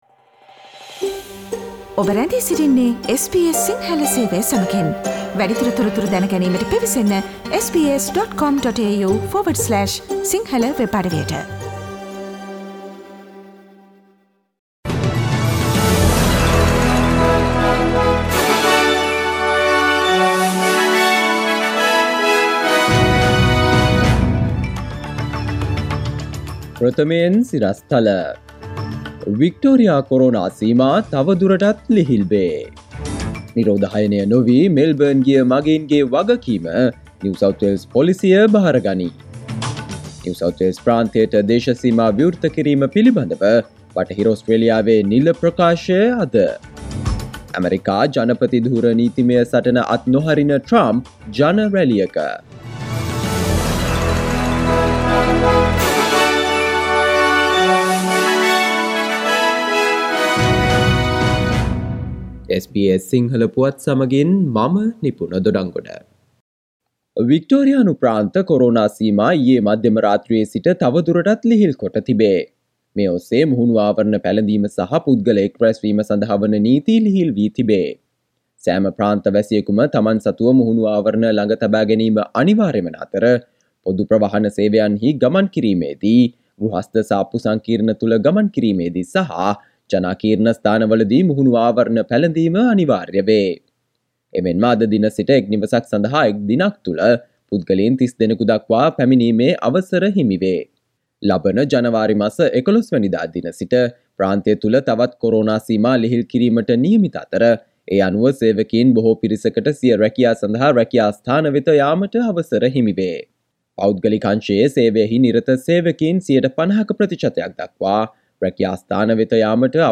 Today’s news bulletin of SBS Sinhala radio – Monday 7 December 2020.